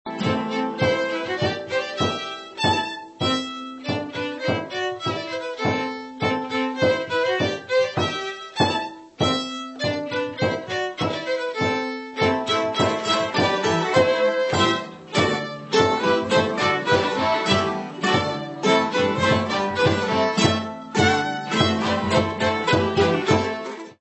Mandolin
Gypsy Violin
Violin
Viola
Guitar
Violoncello
Bodhran